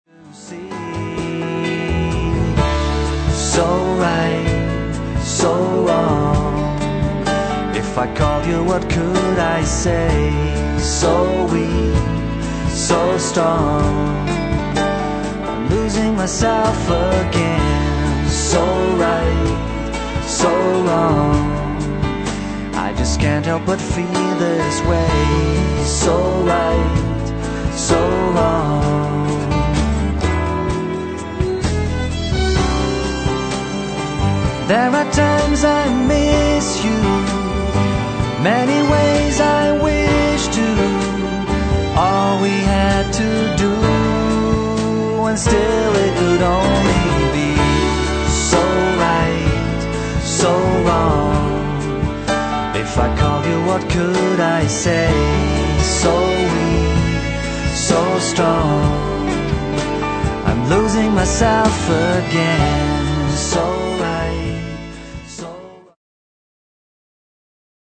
niezwykle melodyjny kawałek